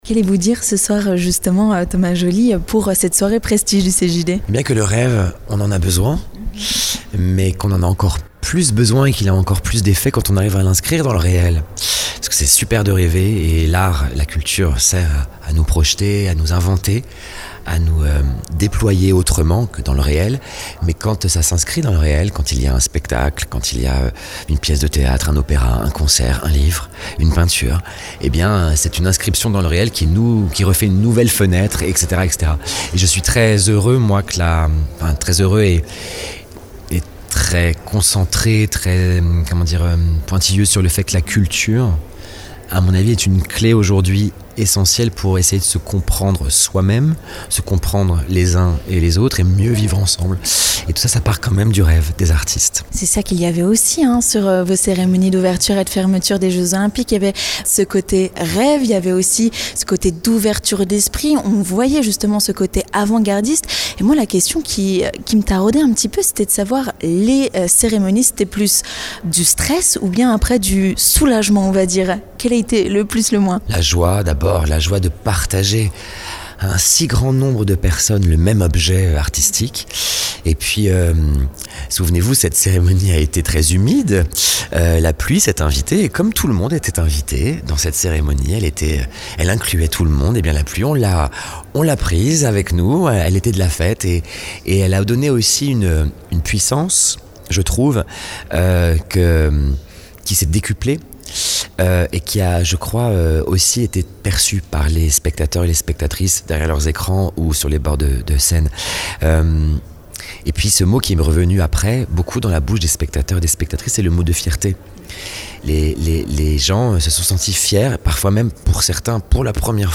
A cette occasion, Vosges FM était partenaire de la Soirée Prestige et a pu poser quelques questions à Thomas Jolly avant son intervention sur scène.